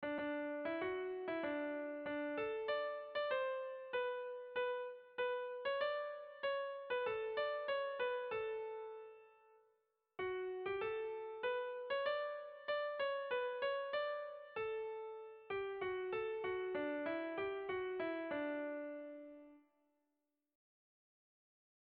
Erromantzea
Mutriku < Debabarrena < Gipuzkoa < Euskal Herria
AB